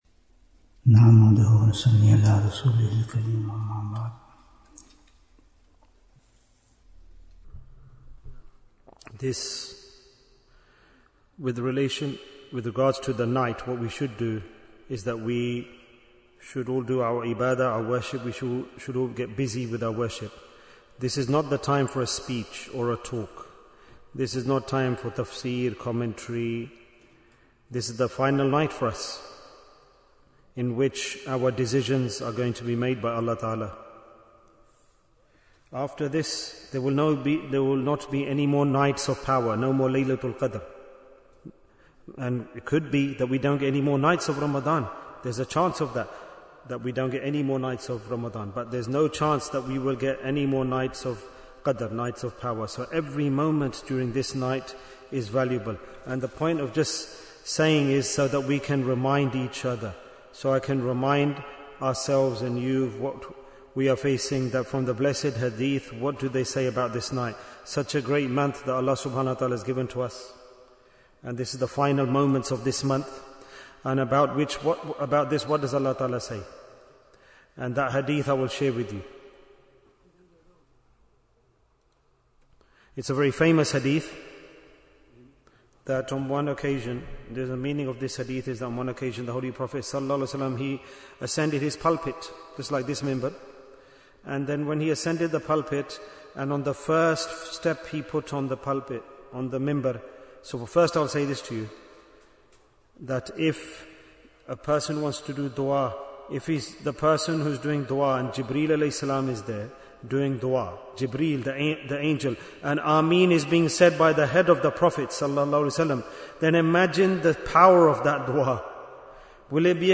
Jewels of Ramadhan 2026 - Episode 38 Bayan, 51 minutes17th March, 2026